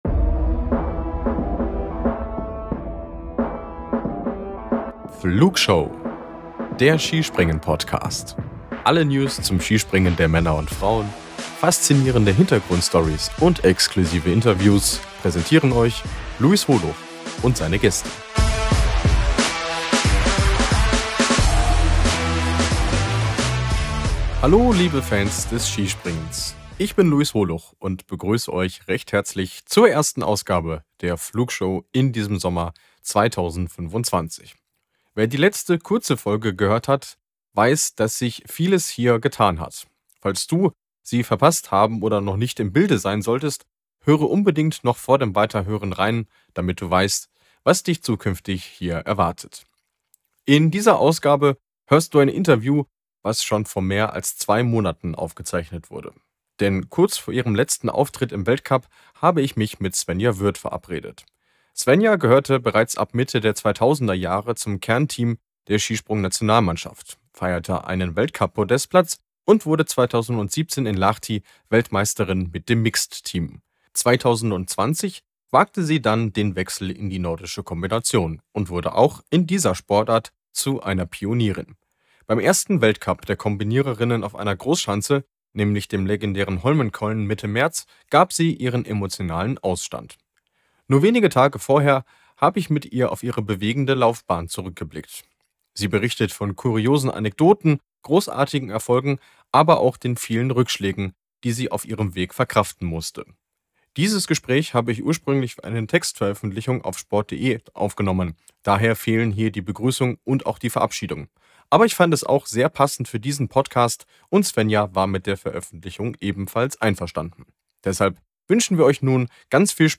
Skisprung- und NoKo-Pionierin Svenja Würth im Interview ~ Wintersport Podcast